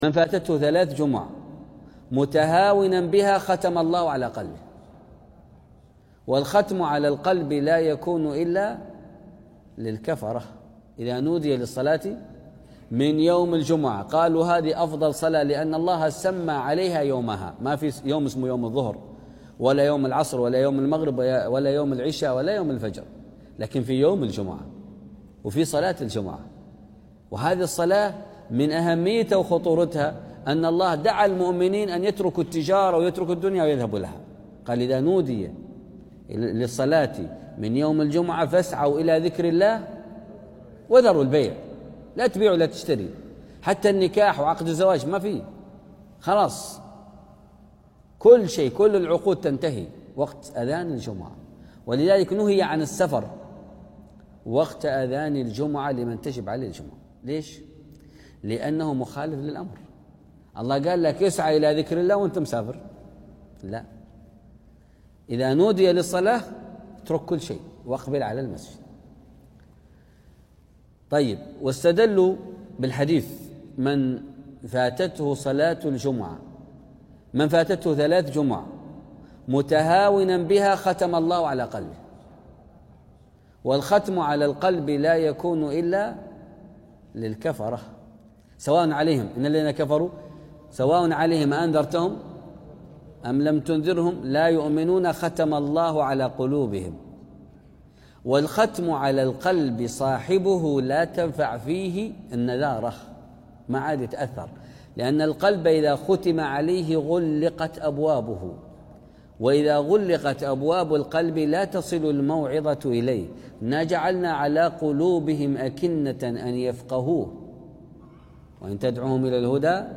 محاضرات موعظه قران محاضره خطبه